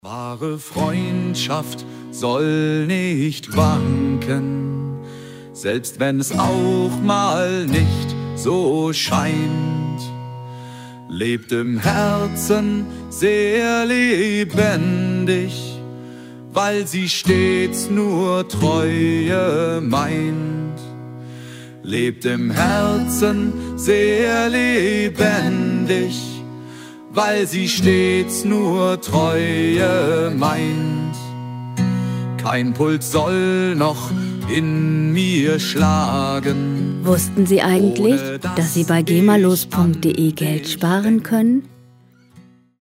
Musikstil: Volkslied
Tempo: 94 bpm
Tonart: H-Dur
Charakter: treu, traditionell